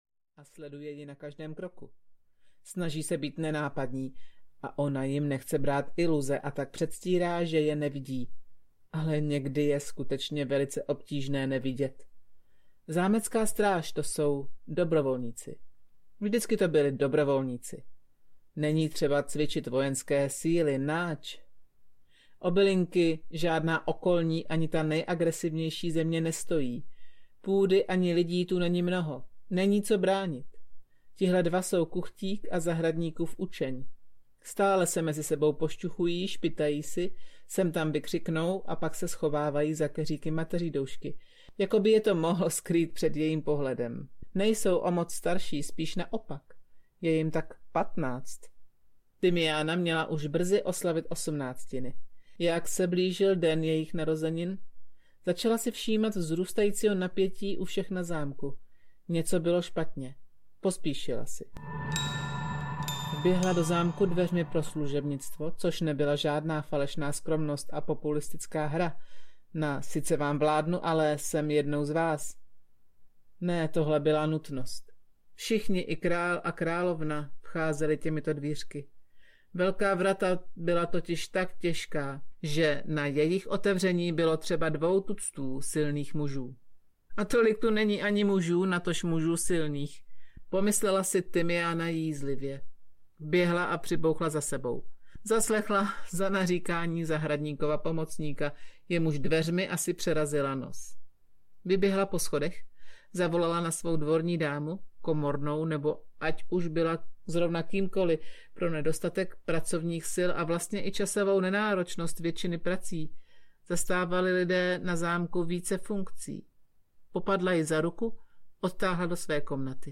50 odstínů tymiánu audiokniha
Ukázka z knihy